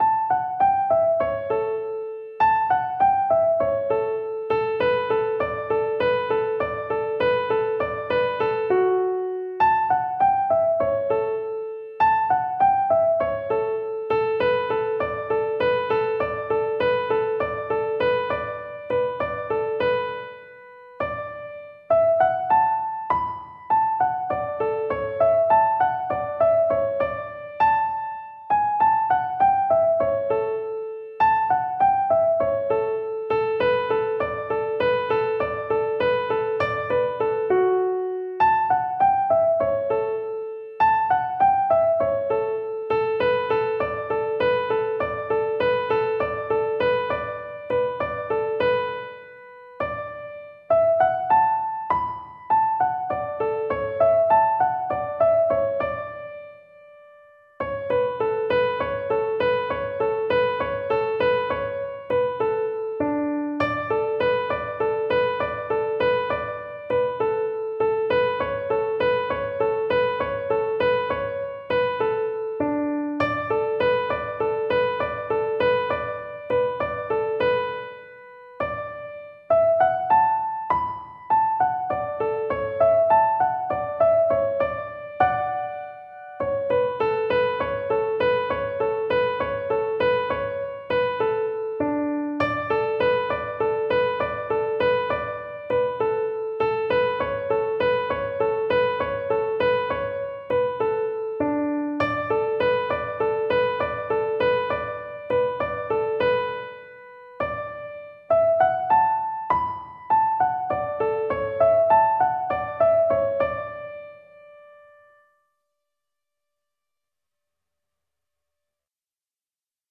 Tap & Sync - Old Timey Music
Below are the tunes with sheet music (PDF) and audio recordings (MP3) played slowly so you can learn them. The letters after each tune name indicate the key.
Pig Ankle Rag D 📄 PDF